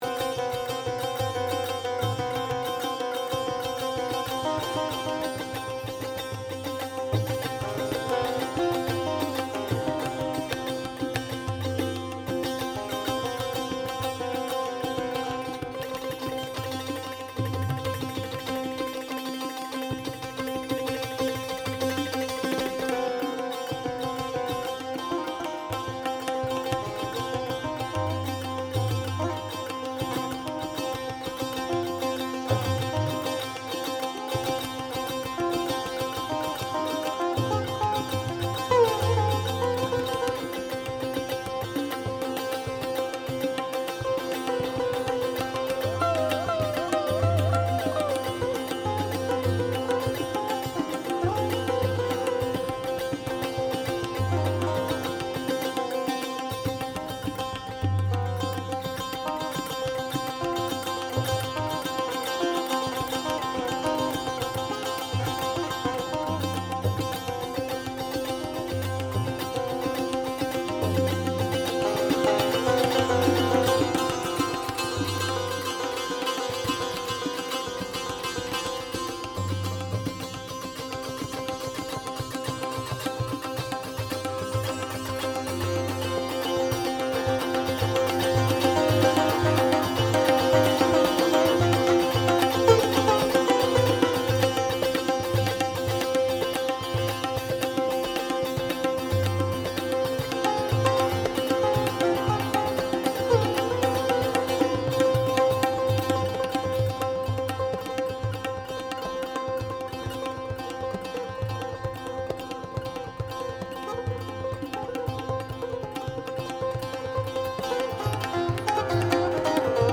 LE SITAR (grand luth à 7 cordes)
LES TABLA (percussion syllabique et digitale)
concert BSN